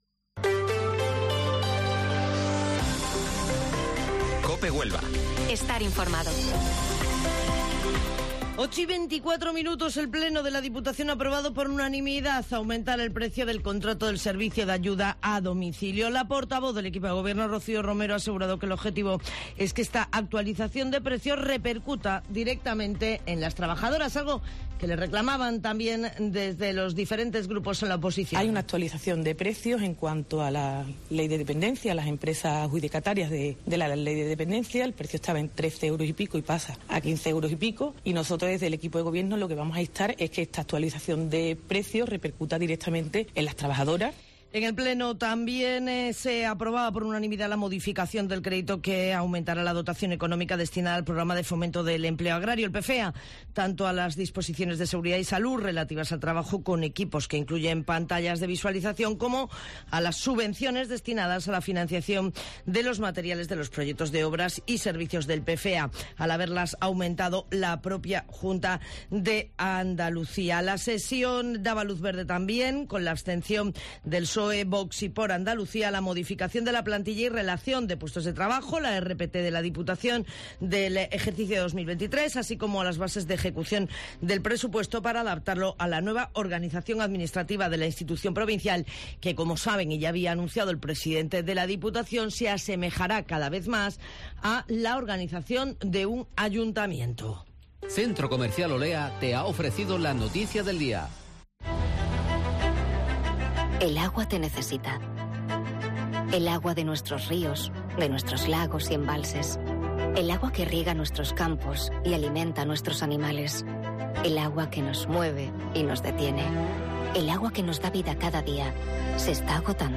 Informativo Matinal Herrera en COPE 29 de agosto